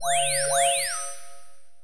sci-fi_power_up_08.wav